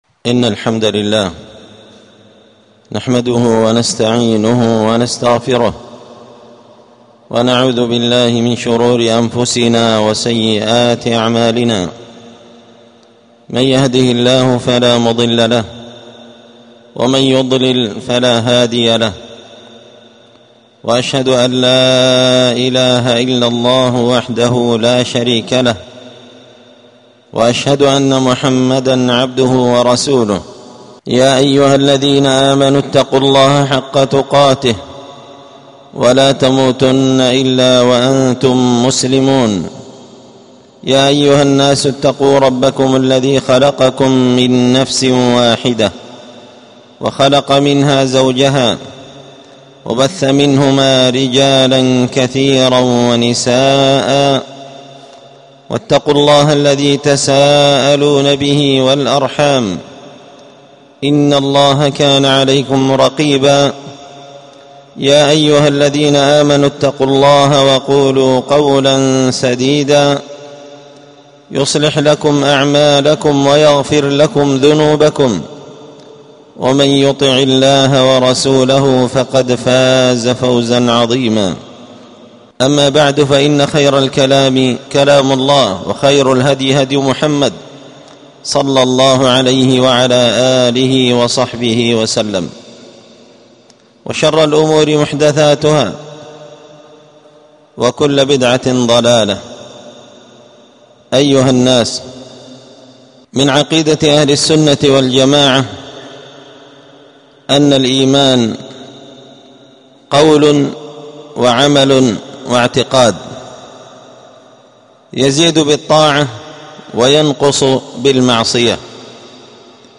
خطبة جمعة بعنوان: {الإيضاح والبيان لأسباب ضعف الإيمان}
ألقيت هذه الخطبة بدار الحديث السلفية بمسجد الفرقان